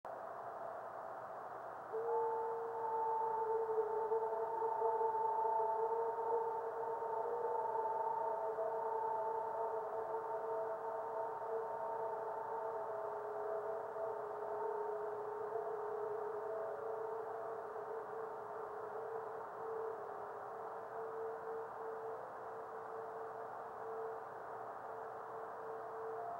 Observer's comments:   Small meteor with a moderately strong radio reflection.